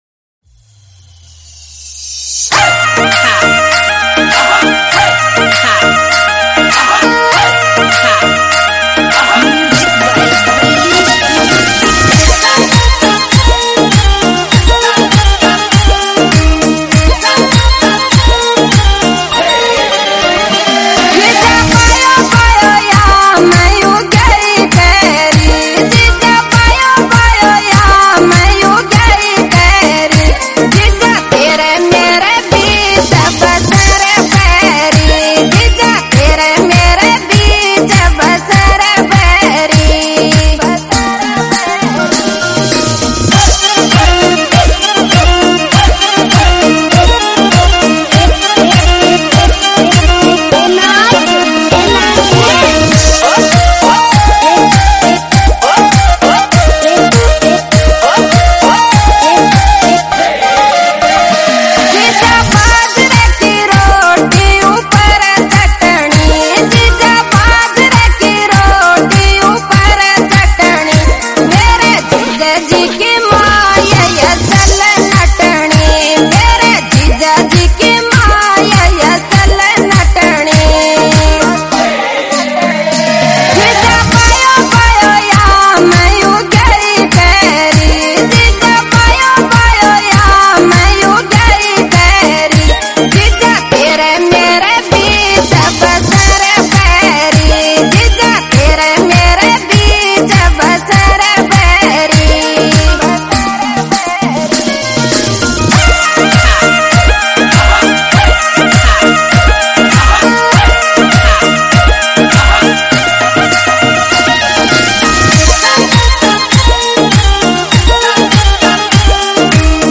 Category: Rajasthani